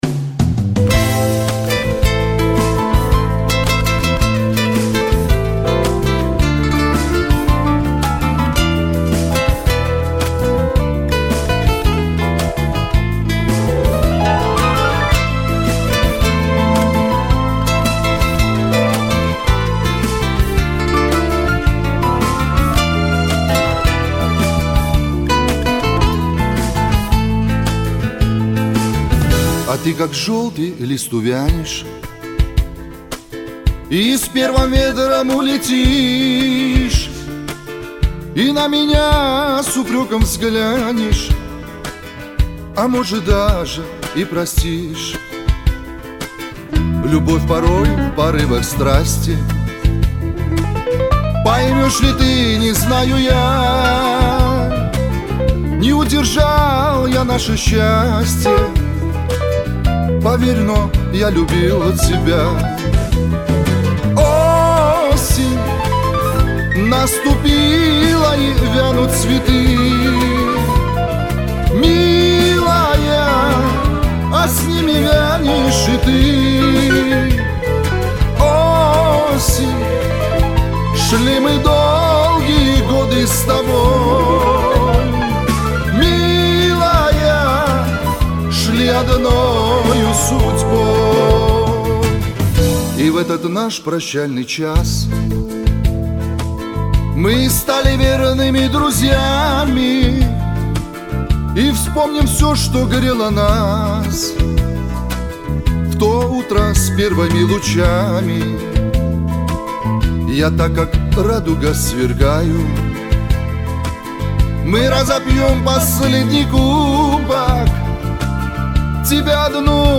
հանգիստ երգ